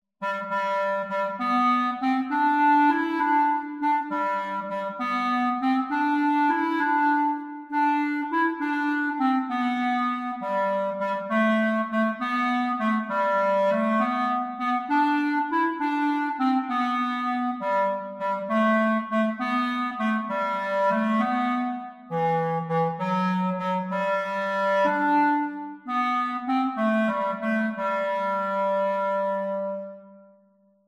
Clarinet Solo